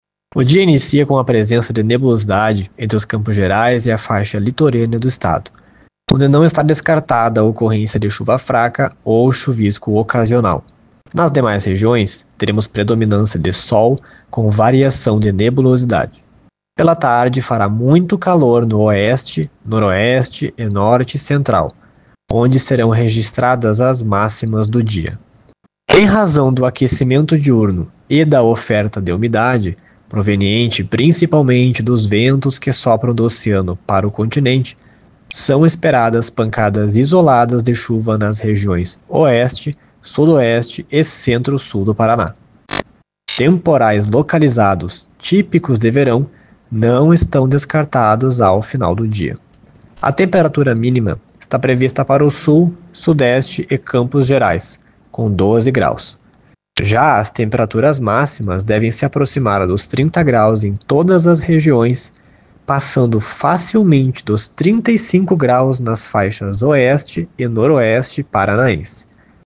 Previsão